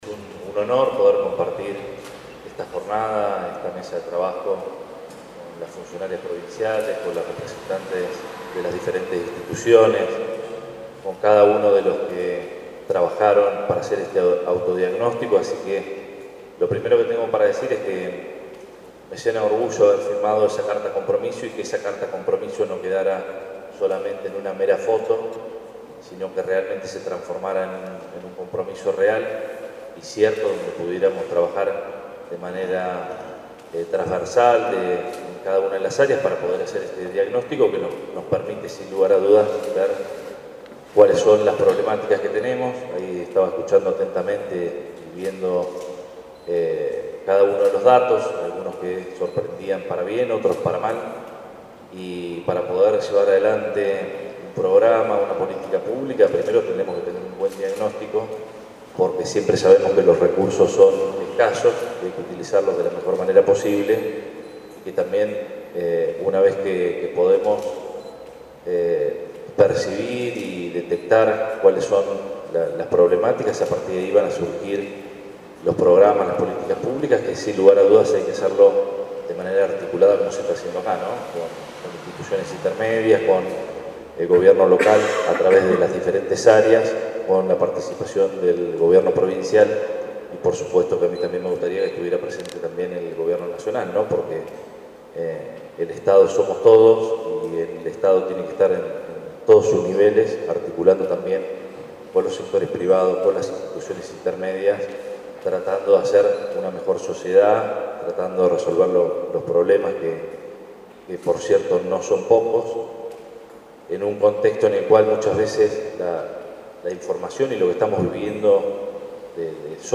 “Hay que felicitar al Intendente por poner el tema de las infancias en el centro de la agenda”, destacaron referentes de MUNA-UNICEF en el marco de la reunión de diagnóstico de la temática a nivel local que se desarrolló en el Salón de Actos del municipio.
28-02-AUDIO-Arturo-Rojas.mp3